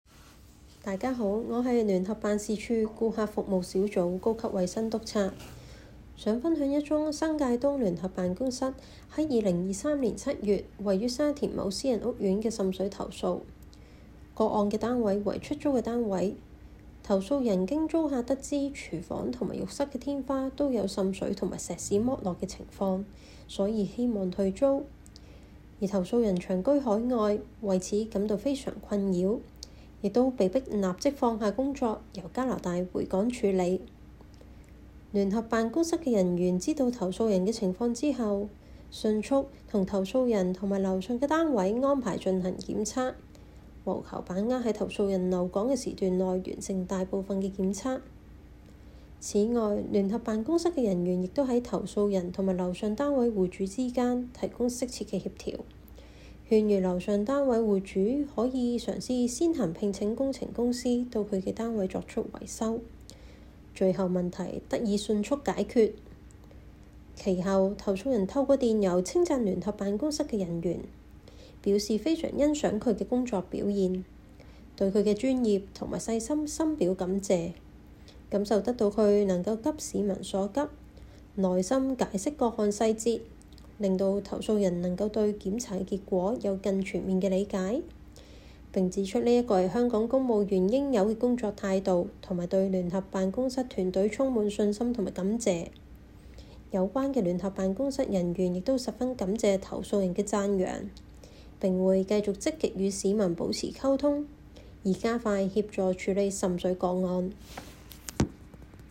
Case No. Year (Region) The voice of Joint Office investigating officer